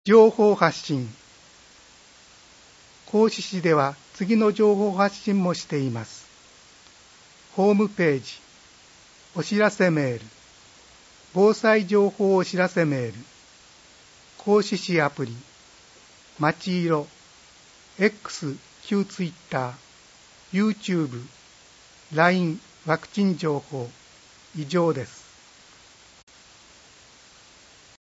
広報こうし令和5年11月号 音訳版